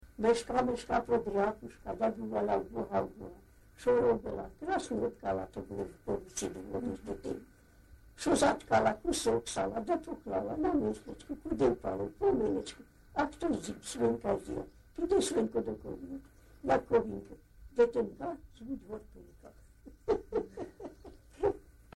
ЖанрЗабавлянки
Місце записус. Олексіївка, Великоновосілківський (Волноваський) район, Донецька обл., Україна, Слобожанщина